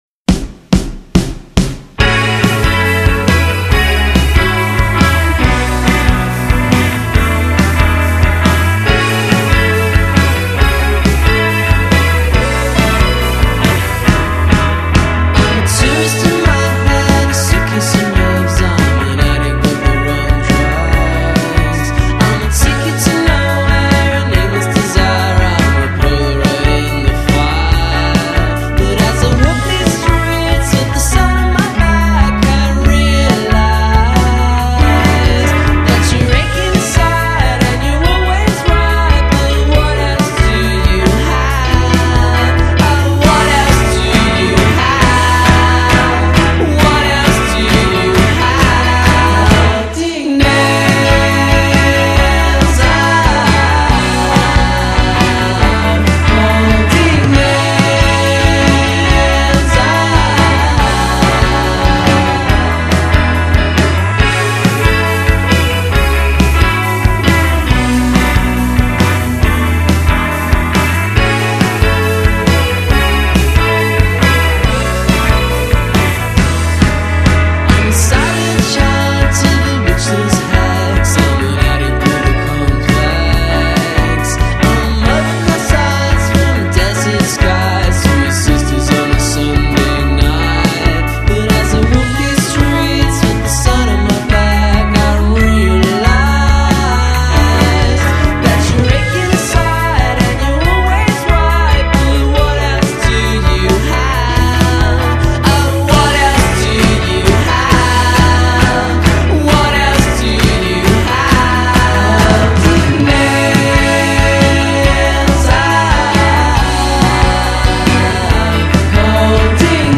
molto più giocato sulla dolcezza